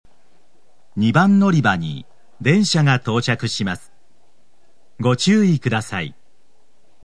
＜スピーカー＞　天井埋込型
＜曲名（本サイト概要）＞　西鉄新主要　／　全線-男性放送
○接近放送 接近放送は、時分・種別を言わない簡易的なものです。１・２番線のみ「電車とホームとの…」の啓発放送が流れます。